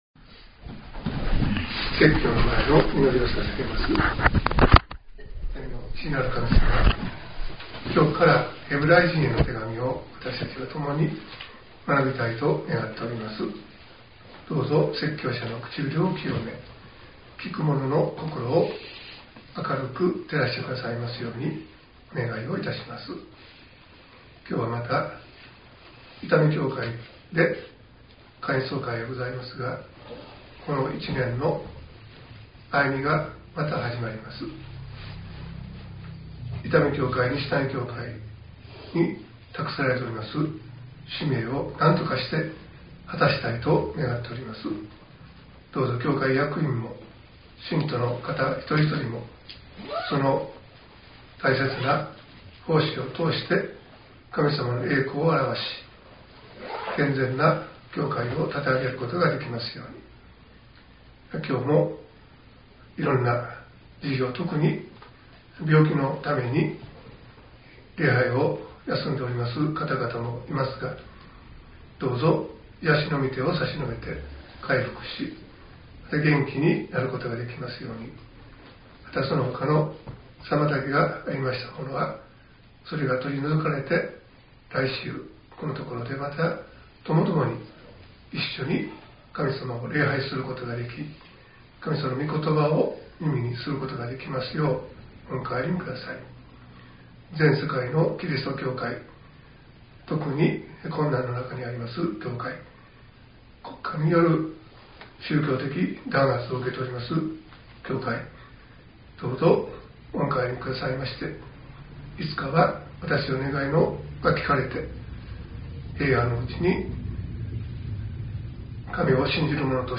.mp3 ←クリックして説教をお聴きください。